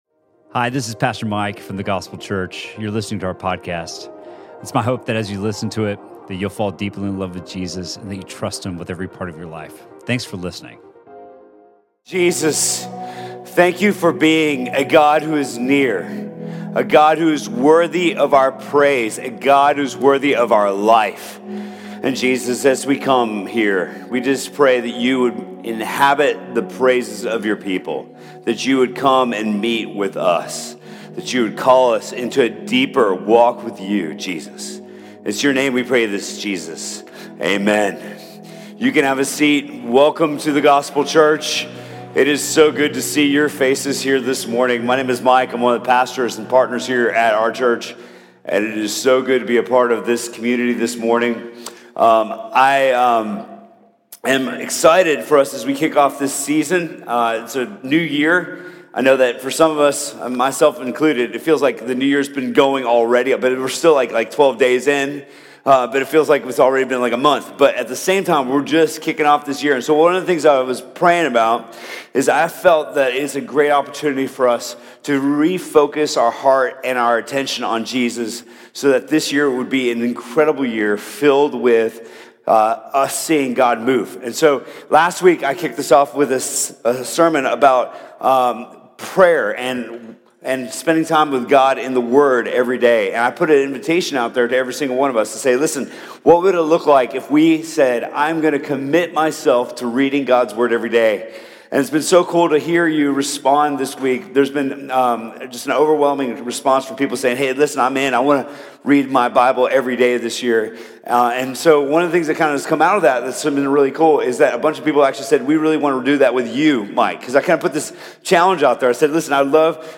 Sermon from The Gospel Church on January 13th, 2019.